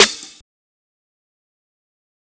808 SLIDE SNARE.wav